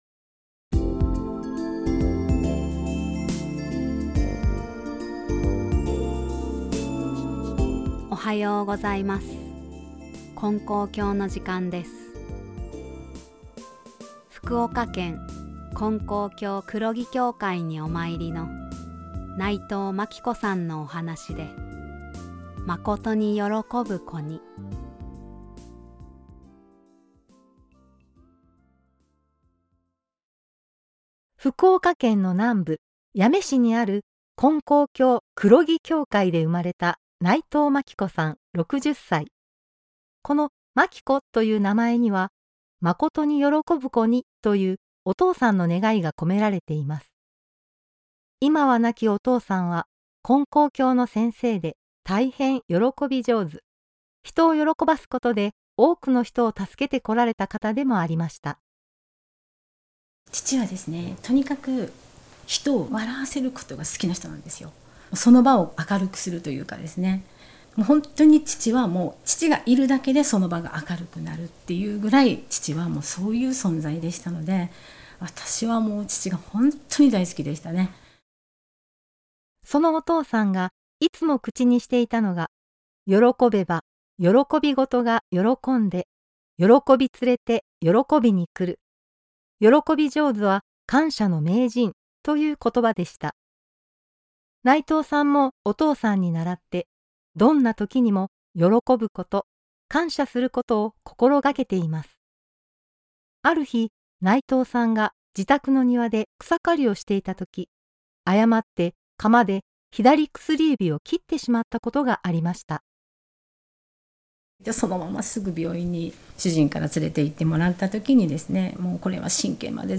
●信者さんのおはなし